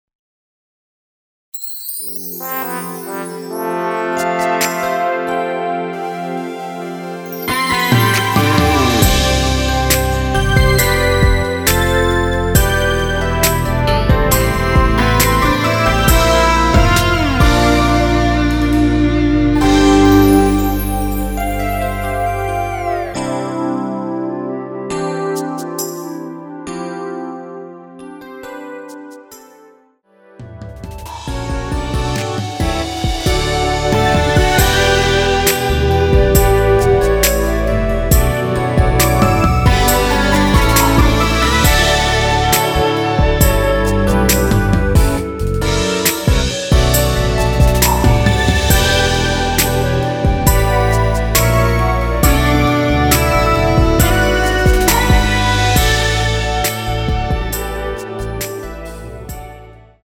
원키에서(-7)내린 멜로디 포함된 MR입니다.
멜로디 MR이라고 합니다.
앞부분30초, 뒷부분30초씩 편집해서 올려 드리고 있습니다.
중간에 음이 끈어지고 다시 나오는 이유는